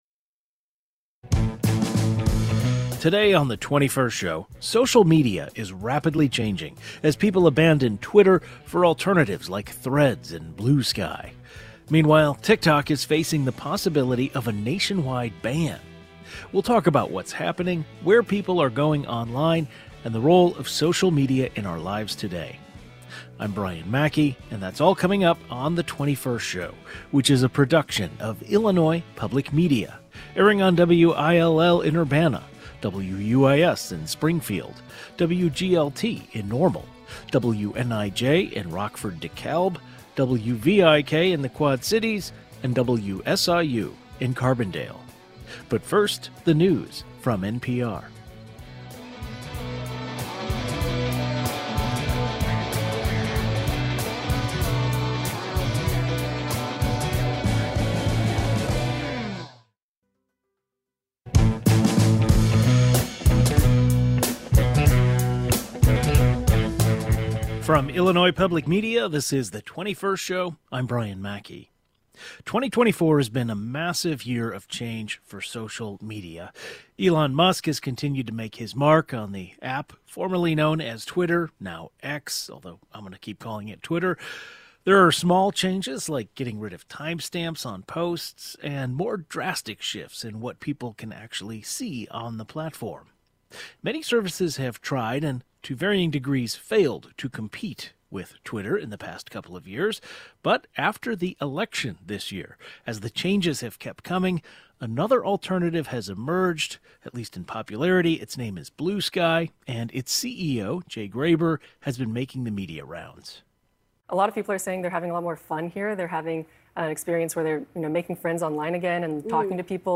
Social media experts and influencers discuss what's happening, the shift to newer platforms, and reflect on the role social media plays in our lives.